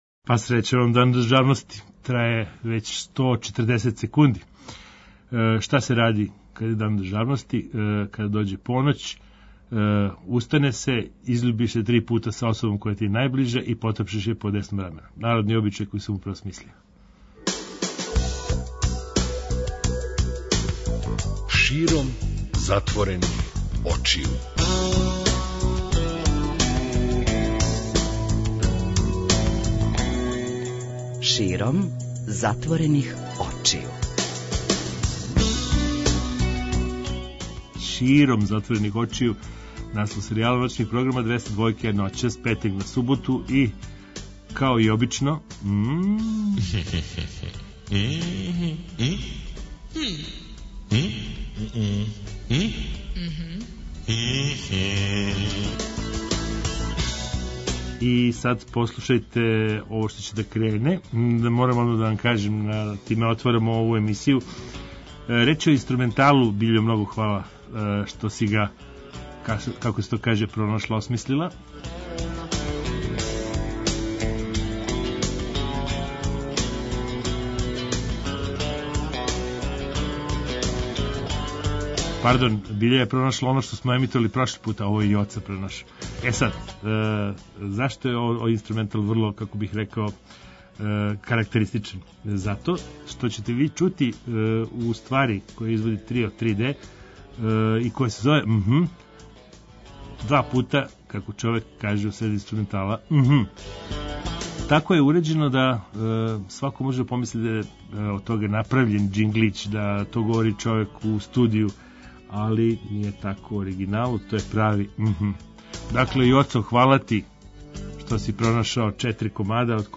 Зато ћемо и ноћас да причамо о неколико тема уз непрекидан контакт са публиком.